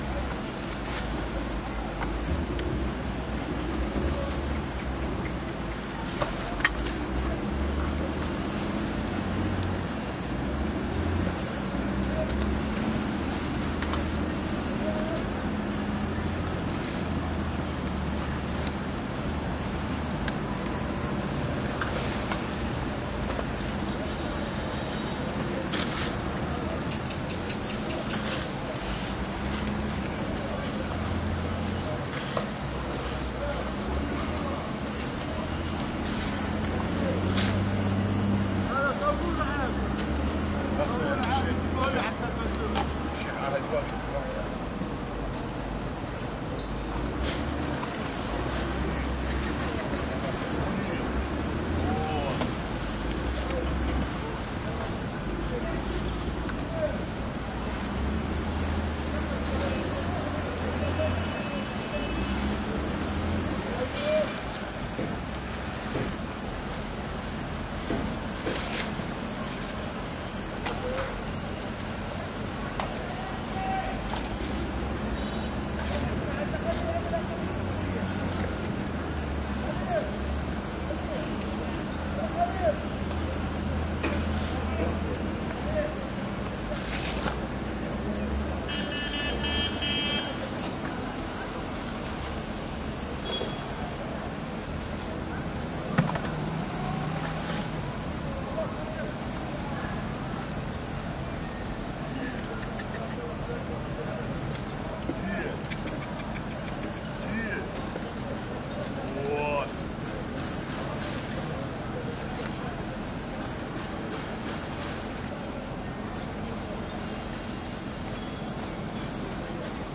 开启空调
描述：打开空调
Tag: 空气 噪音 冷却器